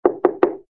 GUI_knock_2.ogg